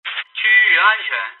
radio_clear.mp3